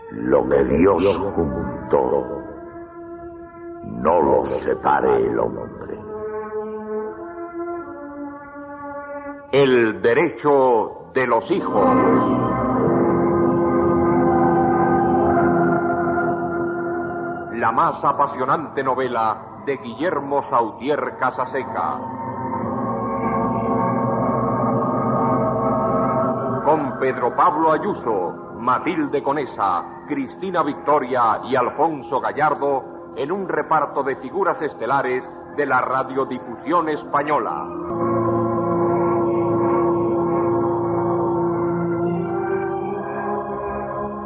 Careta del serial, escrit per Guillermo Sautier Casaseca, amb el repartiment
Ficció